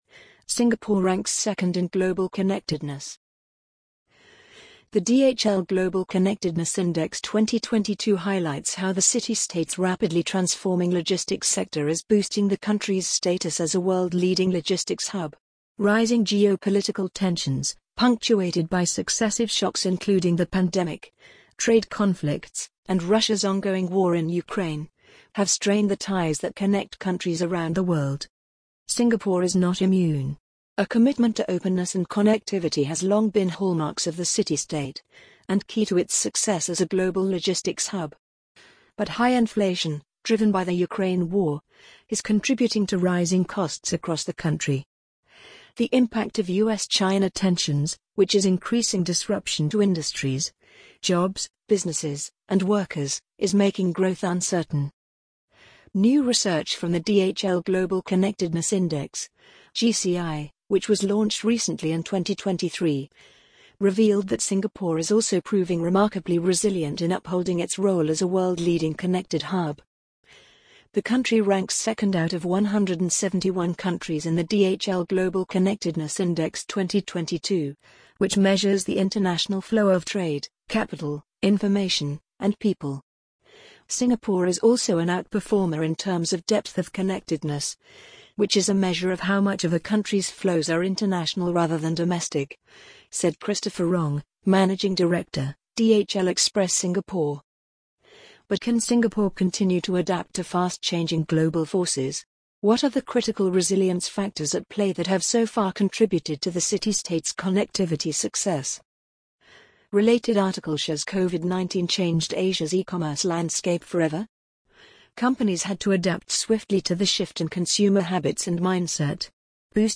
amazon_polly_36214.mp3